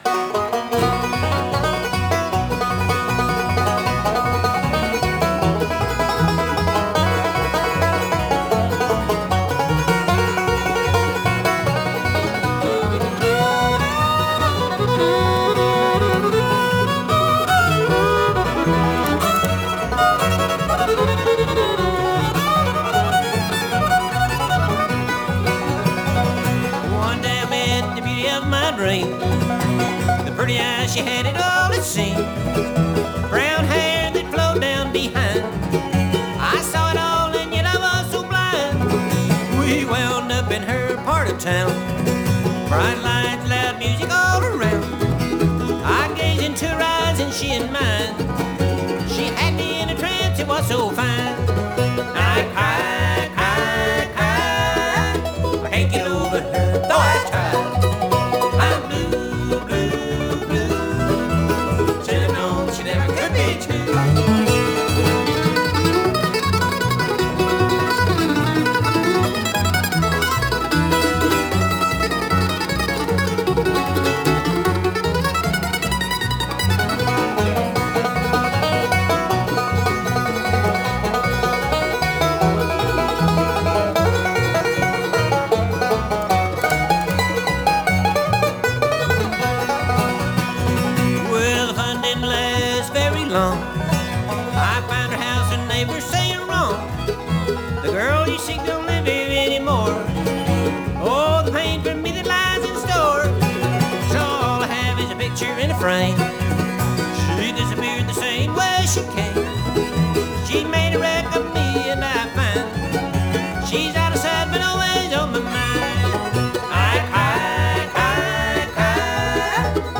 vocals, guitar
mandolin, vocals
banjo
fiddle, vocal
bass, vocal
California for a sold-out concert.
captured (with one microphone)